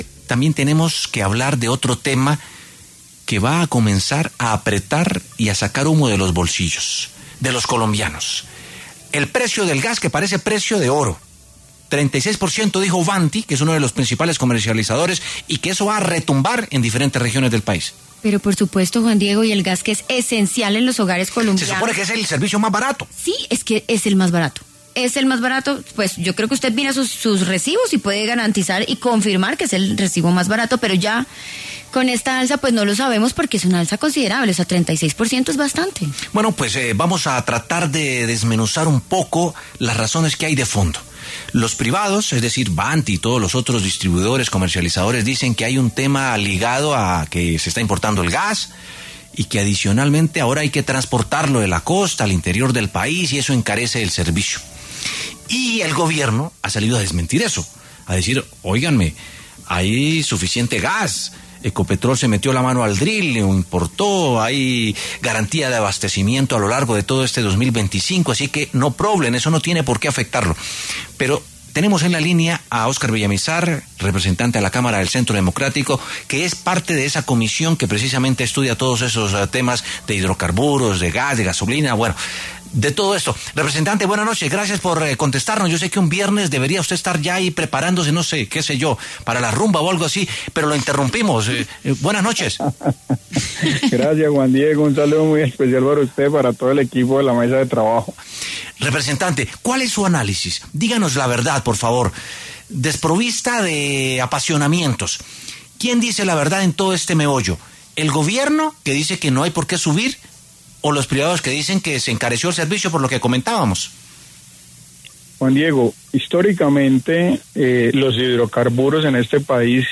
En diálogo con W Sin Carreta, Óscar Villamizar, representante a la Cámara, afirmó que “históricamente los hidrocarburos en este país se reponen con unas reservas, y hoy esas reservas son las que no tiene claro hoy el país por que acabaron con los contratos de exploración y explotación, lo que genera una inseguridad jurídica y energética”.